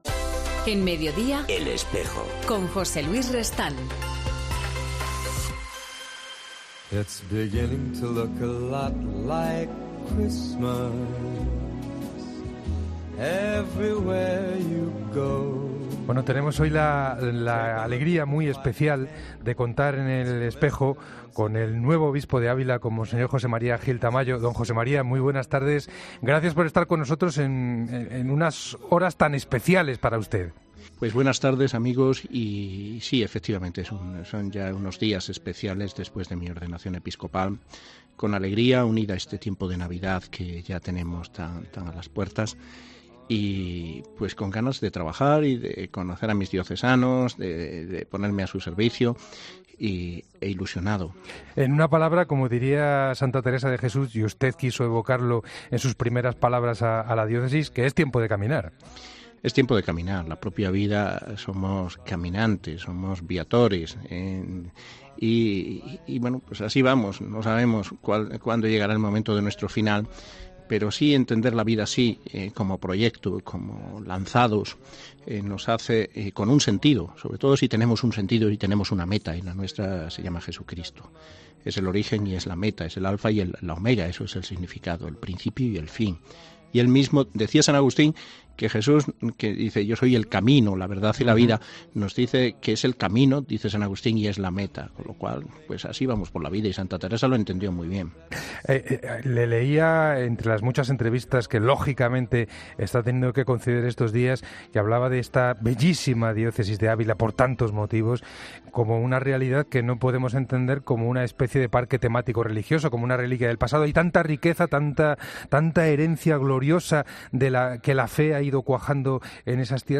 El nuevo obispo de Ávila, José Mª Gil Tamayo ha explicado en El Espejo que está deseando recorrer los rincones de la diócesis y empezar a trabajar.